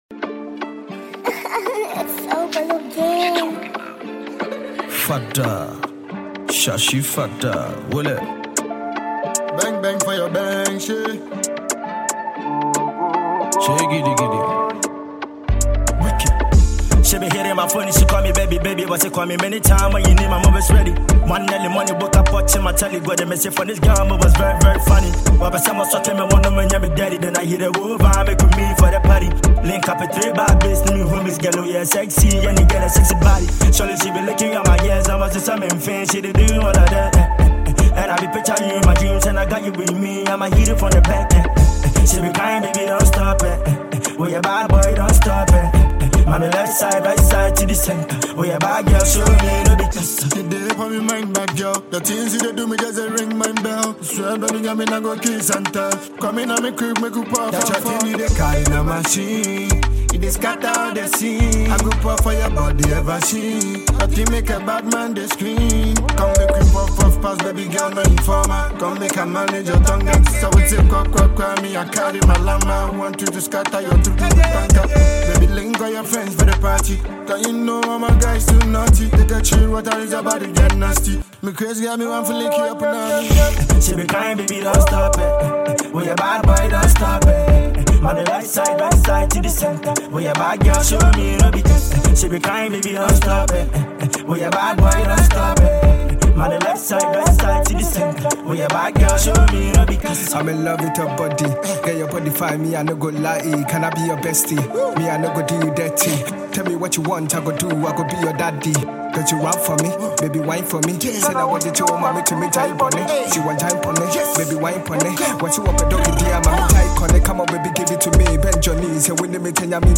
Ghanaian music group
afrobeat hiphop song